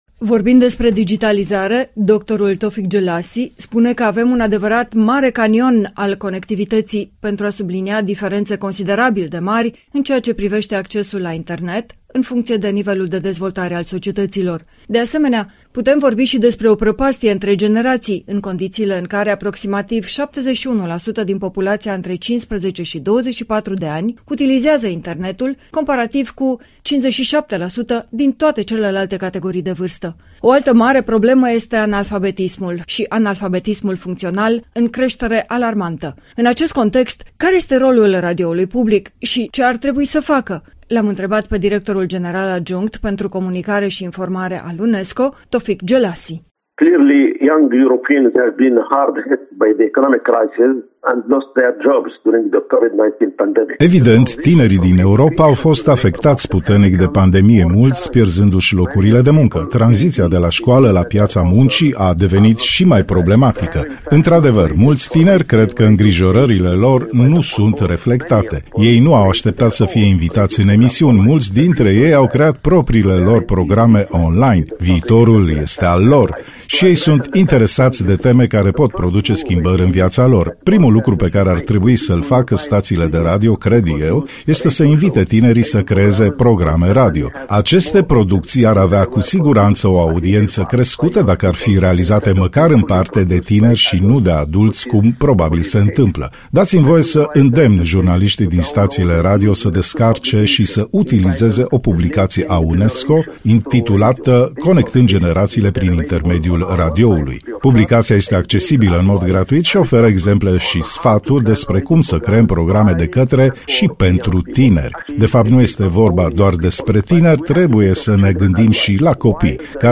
”Radioul nu a încetat să se reinventeze” / AUDIO – Interviu în exclusivitate -UNESCO